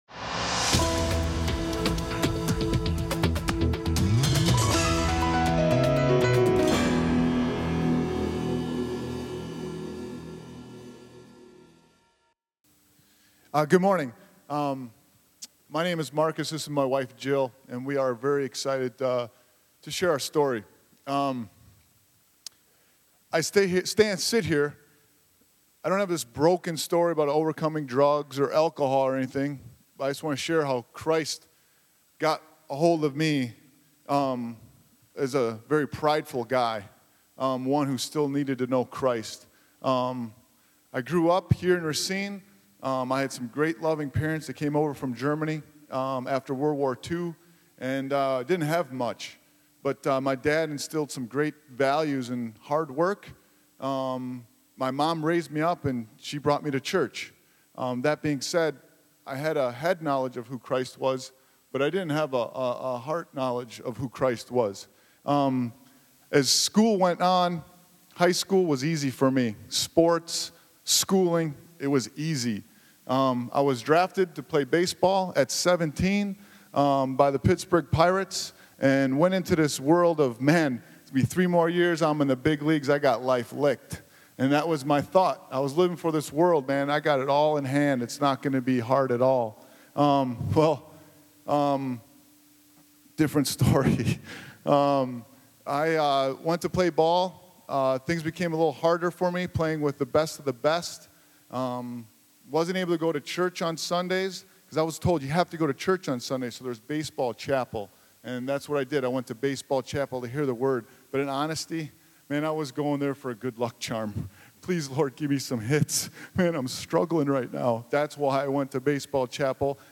Gospel Sermon